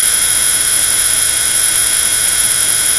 F M合成 " 3mod; 909 Hz 10d B (A S) + 9090 Hz 6d B ( Sq) + 11111 Hz .5dB
描述：电子噪声电子燃烧嗡嗡声尖锐的尖叫声
Tag: 燃烧 嗡嗡声 电子 噪声 嘈杂 尖叫 刺耳